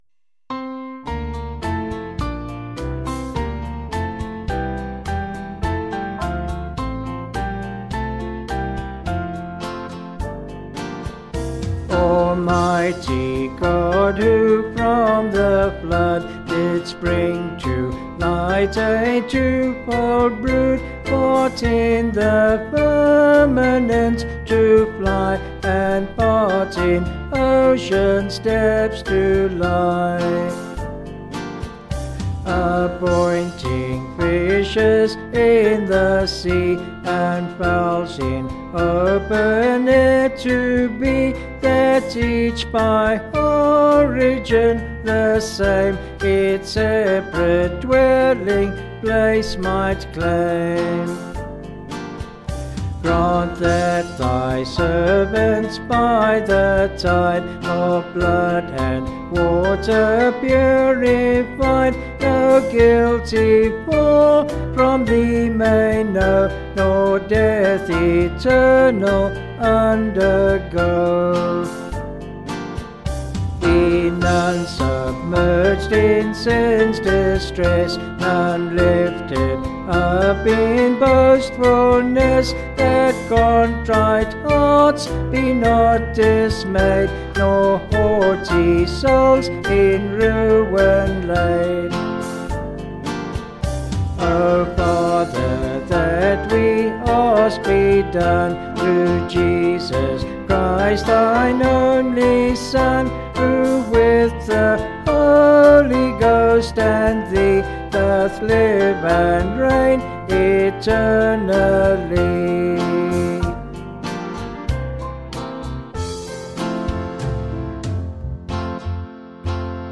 Vocals and Band   263.4kb Sung Lyrics